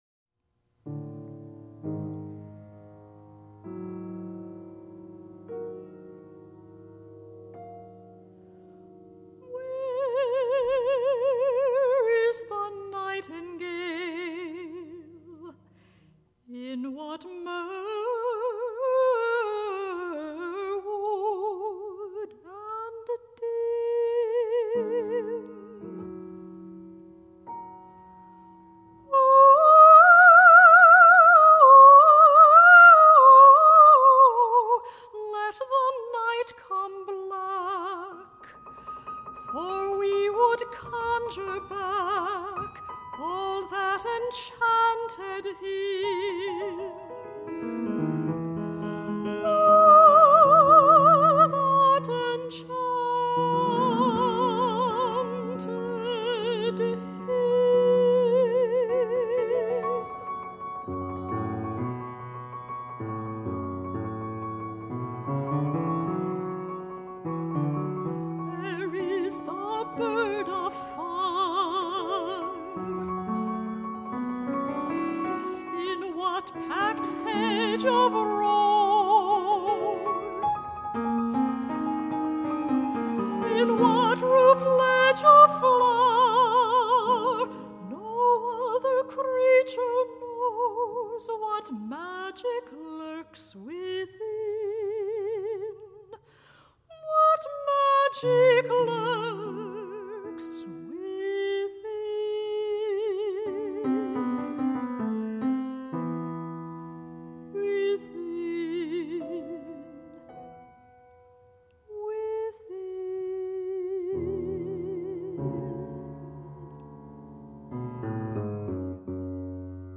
for Soprano and Piano (1982)